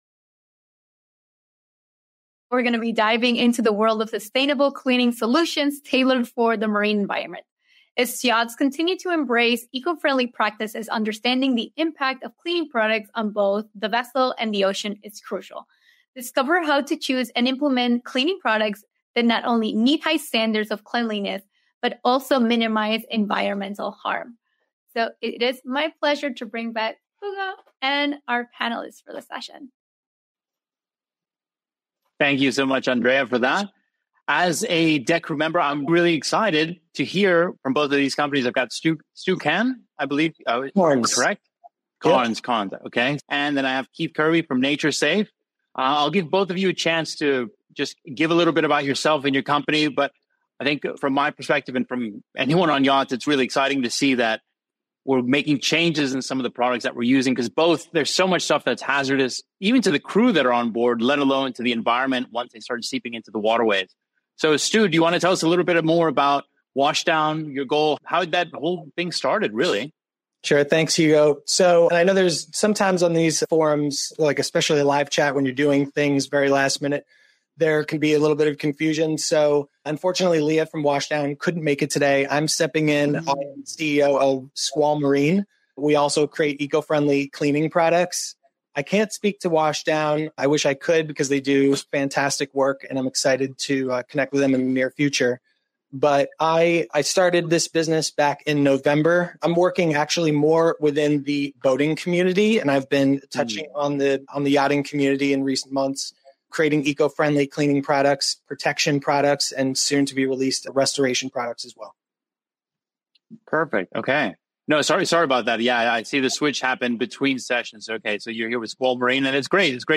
Join us in an enlightening session where sustainable cleaning solutions for the marine environment take center stage.